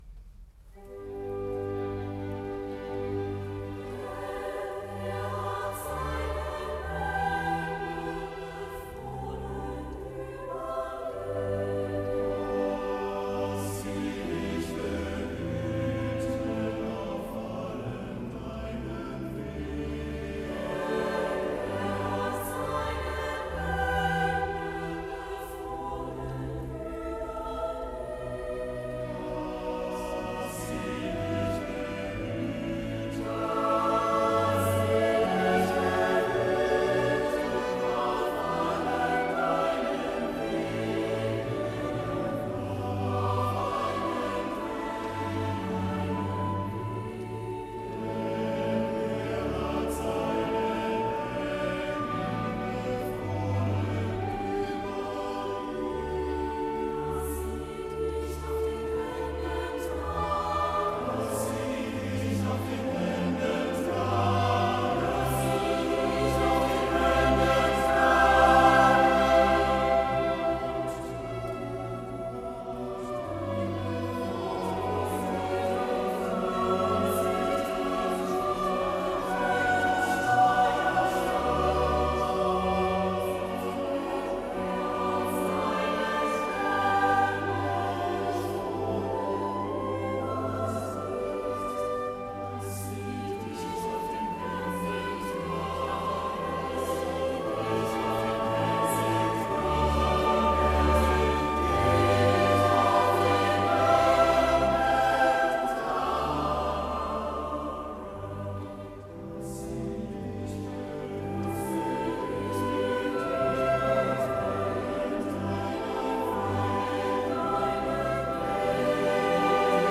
Dezember - MünchenKlang e.V. Chor und Orchester
Aufnahme vom 27.7.2019 in St. Matthäus München